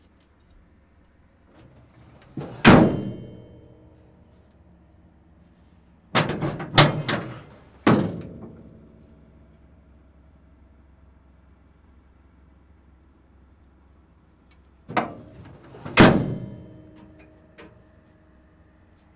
transport
Bus Hood Open Close 4